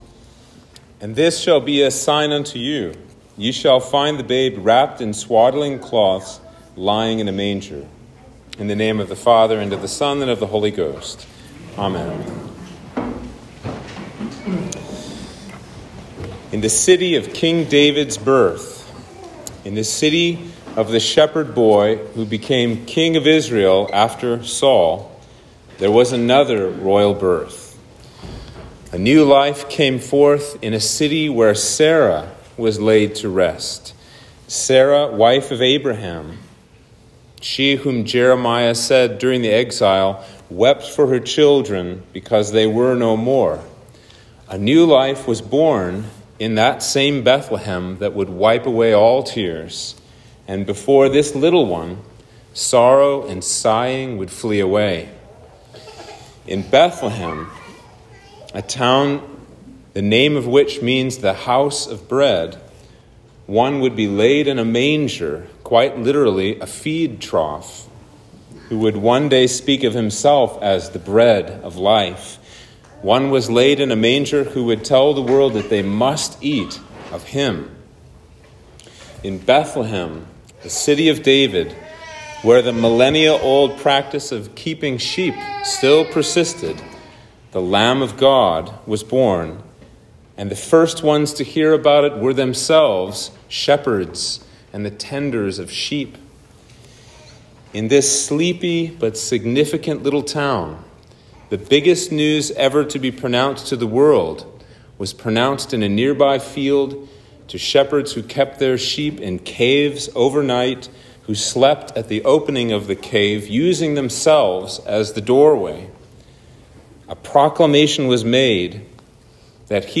Sermon for Christmas Eve